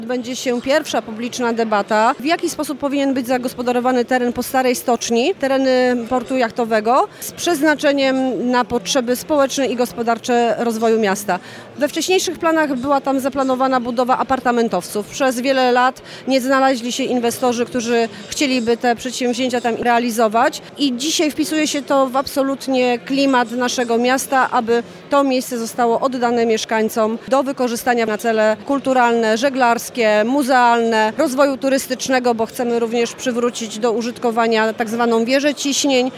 Niewykorzystany potencjał terenów przy porcie jachtowym w Świnoujściu oraz wizja ich zagospodarowania stanie się tematem sobotniej publicznej debaty, w której udział wezmą eksperci, mieszkańcy oraz społecznicy. Jak podkreśla Joanna Agatowska, prezydent Świnoujścia, to ważne spotkanie ma szansę wyznaczyć kierunki rozwoju tej części miasta.